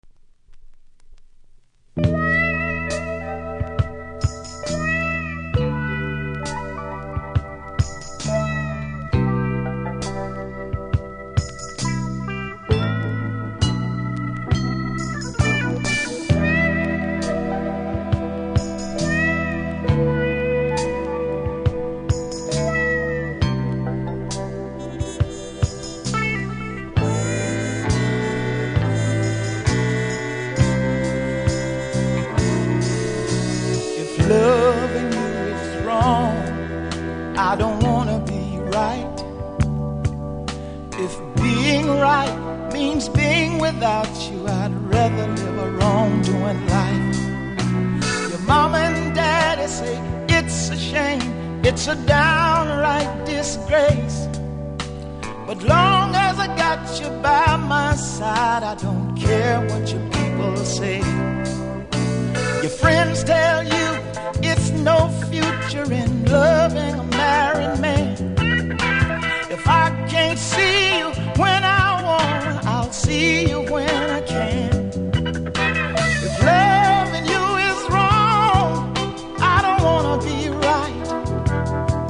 70'S MALE
Vinyl
プレイは問題ないレベルだと思いますが多少ノイズ感じますので試聴で確認下さい。